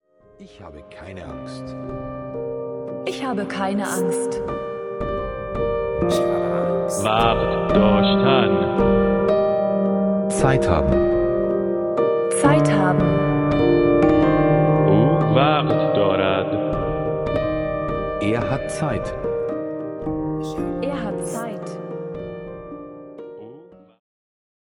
Electronic Organ
Kamanche